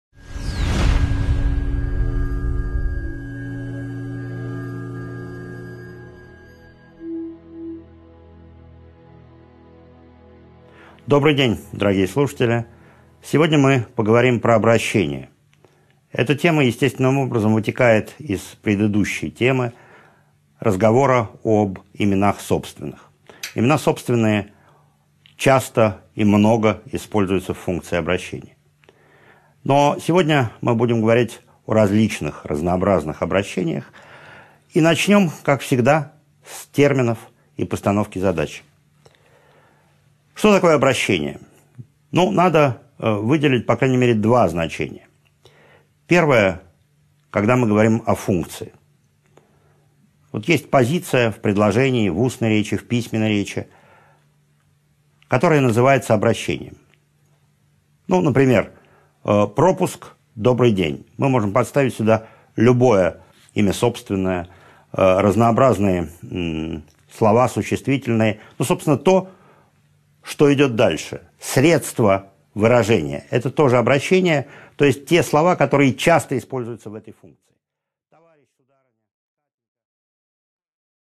Аудиокнига 5.2 Особенности и типы обращений | Библиотека аудиокниг